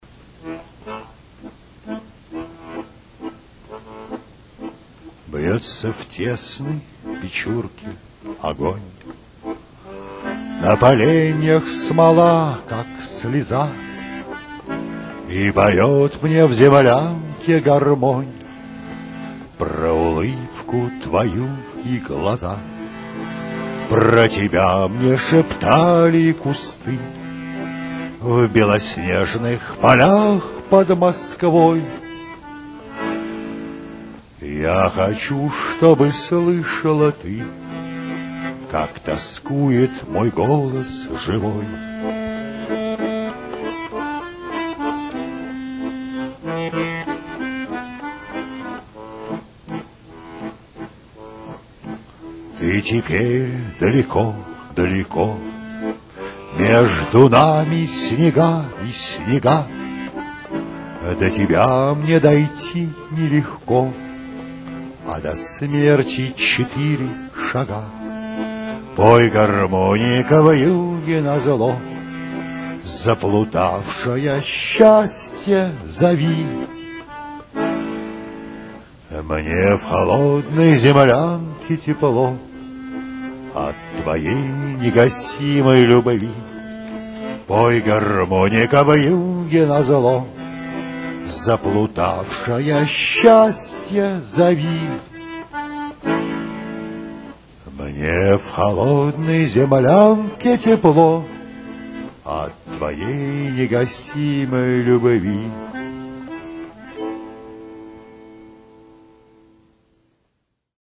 Sprecher russisch, tiefe, volle Stimme
Sprechprobe: Industrie (Muttersprache):
Deep, reach voice